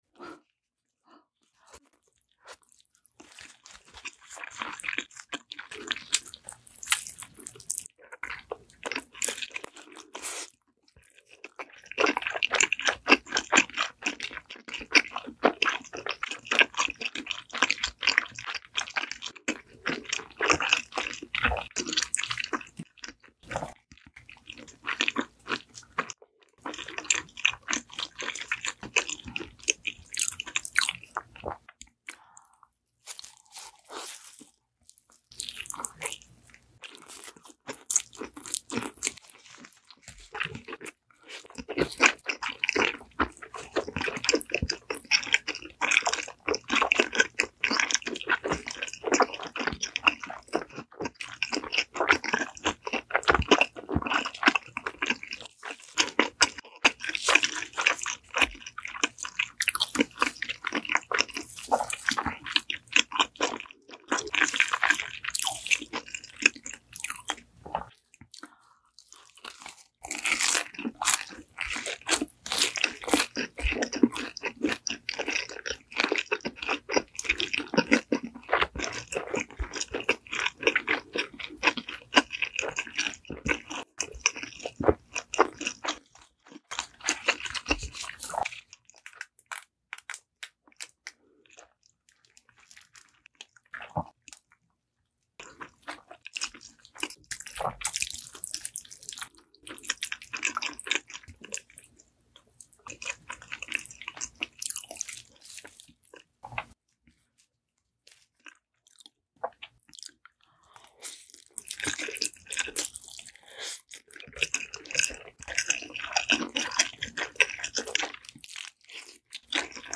Cheese Pizza And Buldak Mukbang